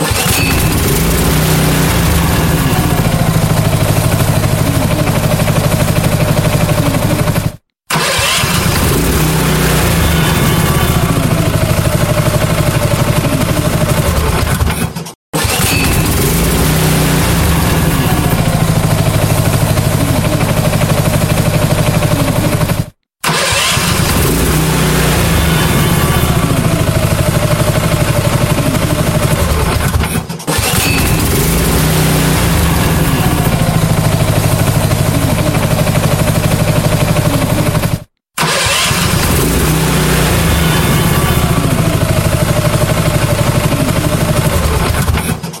Kategorie: Klingeltöne